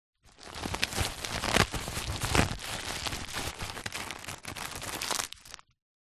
Звуки аптечки
Звук вскрытия аптечки из пленки